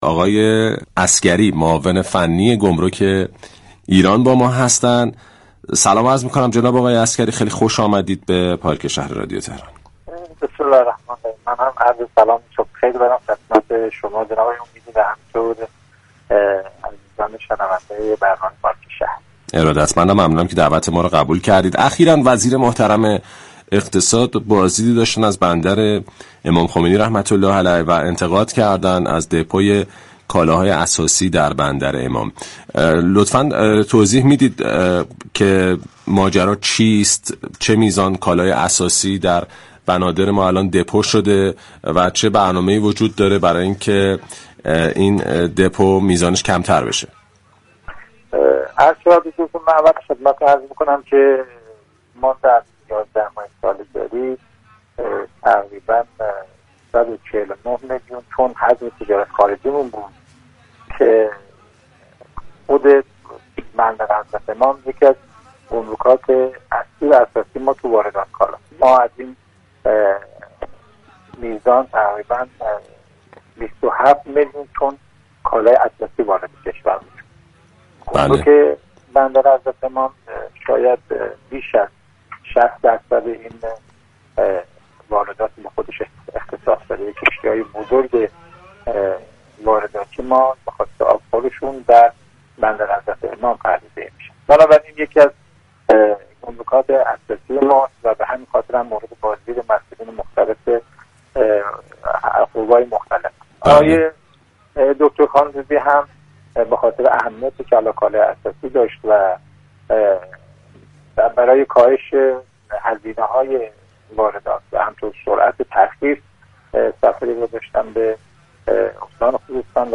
به گزارش پایگاه اطلاع رسانی رادیو تهران، فرود عسگری معاون فنی گمرك كشور در گفتگو با پارك شهر رادیو تهران در خصوص بازدید وزیر اقتصاد و دارایی از بندر امام خمینی(ره) گفت: بندر امام یكی از گمرك‌های اصلی كشور در واردات كالاهای اساسی است و بیش از 60 درصد واردات كشور را به خود اختصاص داده است.